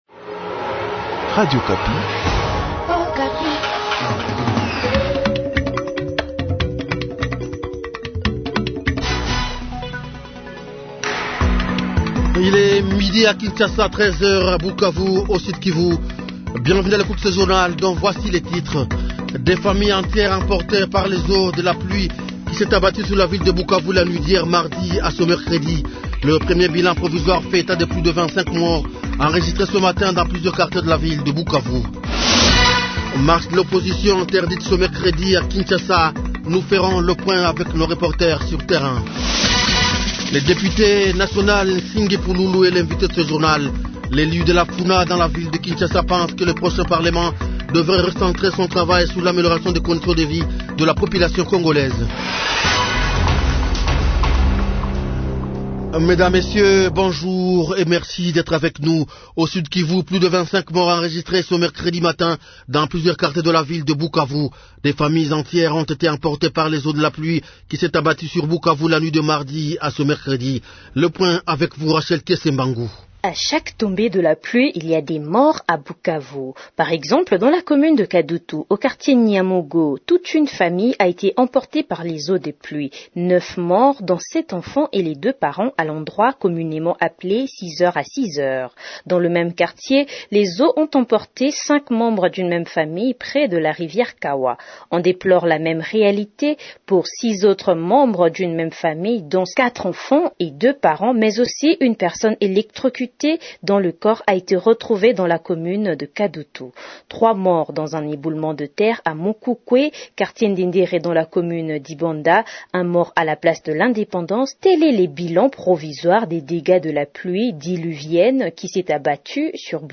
Invité : Pitshou Nsingi Pululu, député national.